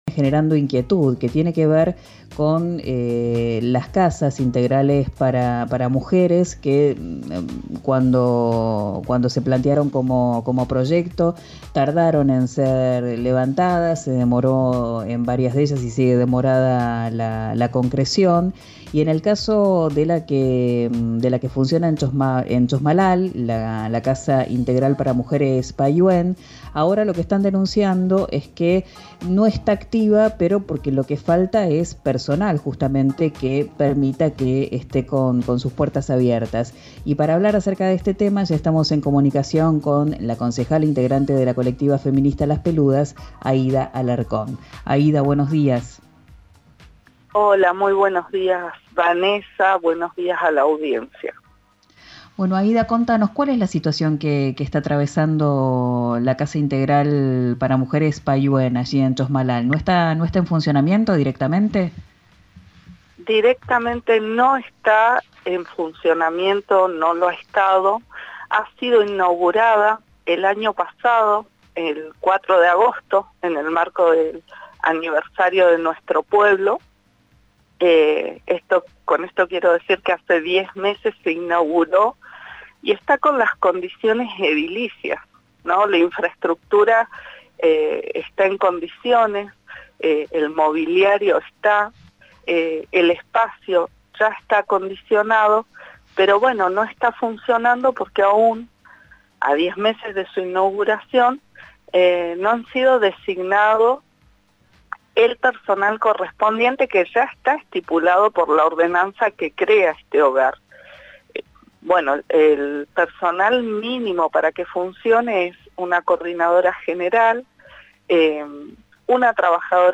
La concejala e integrante de la Colectiva Feminista Las Peludas, Aida Alarcón, relató a RIO NEGRO RADIO la situación e manifestó que es imprescindible su funcionamiento debido a que pretende atender a toda la región.